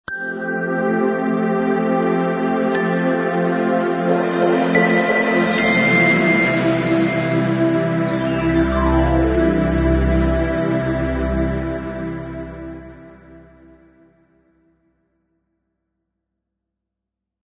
44.1 kHz 明亮简洁片头音乐 全站素材均从网上搜集而来，仅限于学习交流。